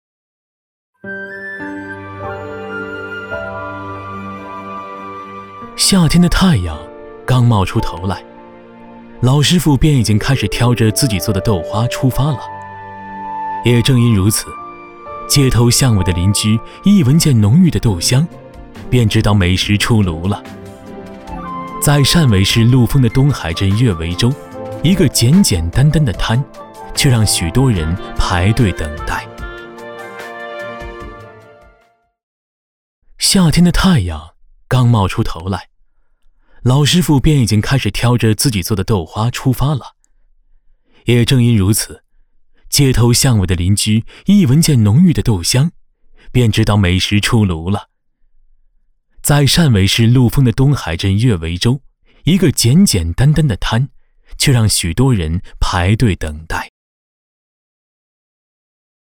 纪录片-男19-舌尖风-美食.mp3